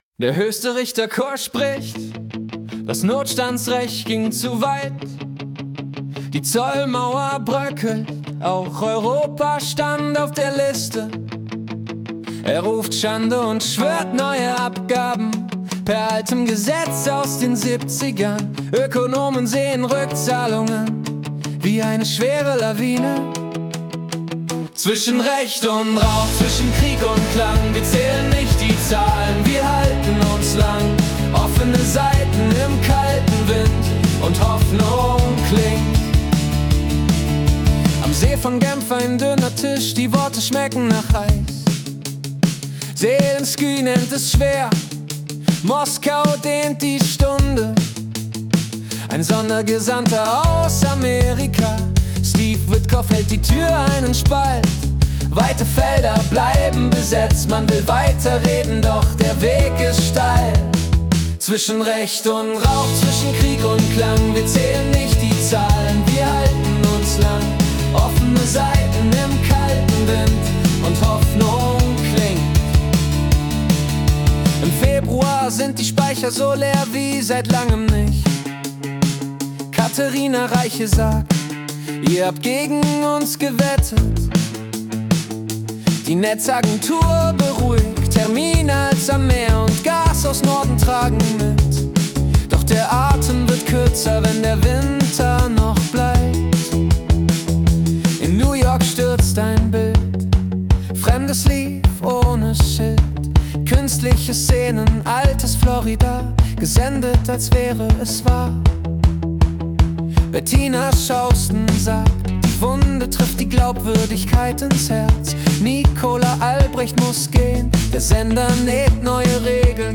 Die Nachrichten vom 21. Februar 2026 als Singer-Songwriter-Song interpretiert.
Jede Folge verwandelt die letzten 24 Stunden weltweiter Ereignisse in eine originale Singer-Songwriter-Komposition.